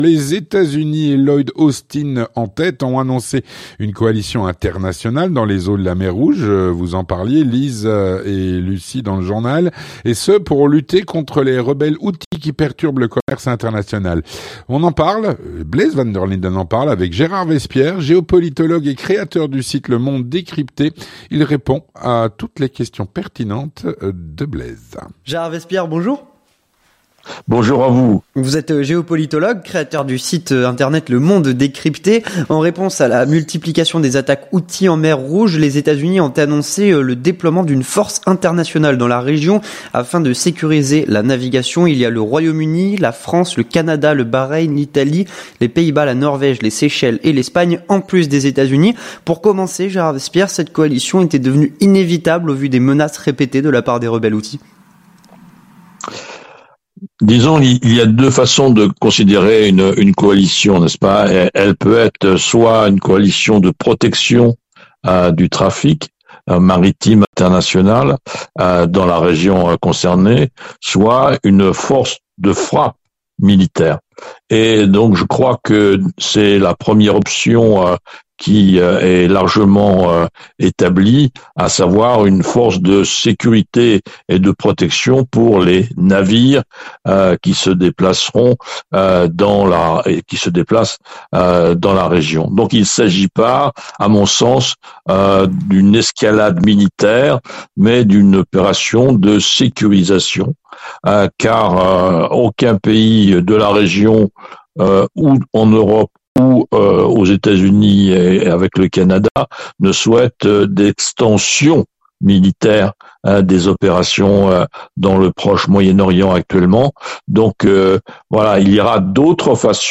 L'entretien du 18H - Coalition internationale en Mer Rouge.